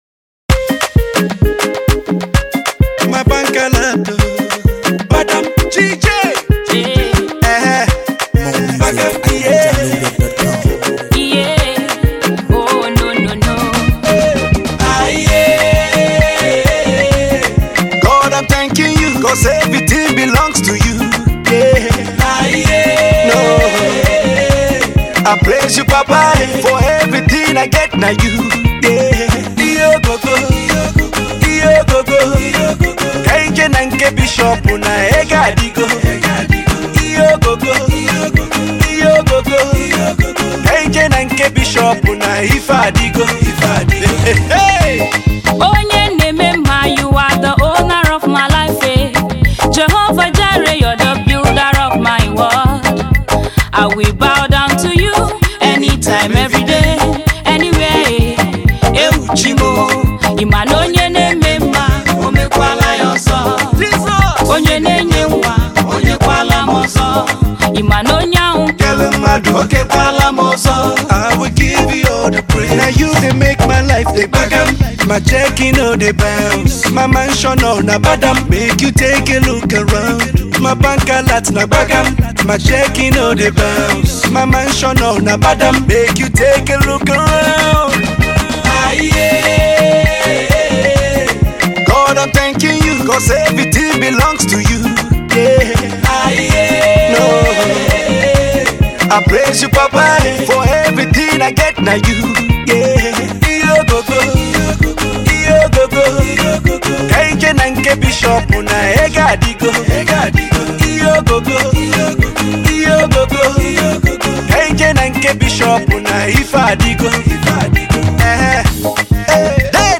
Gospel remix